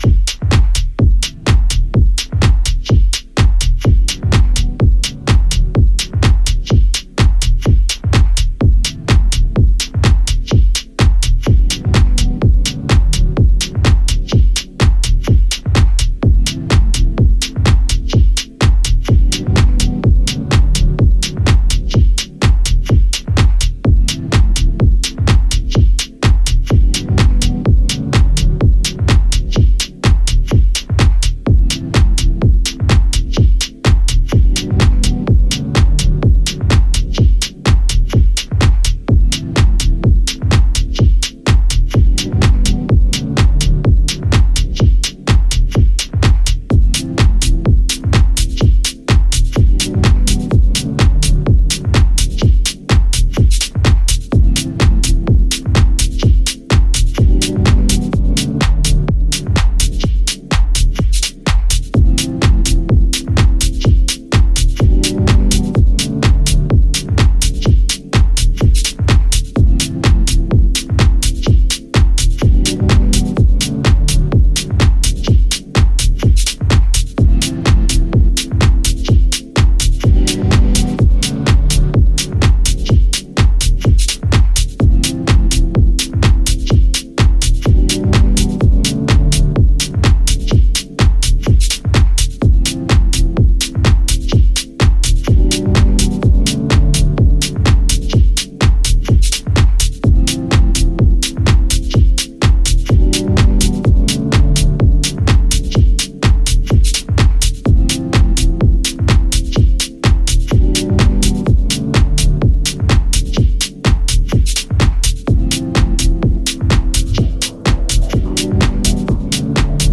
Here we have three super-deep yet driving cuts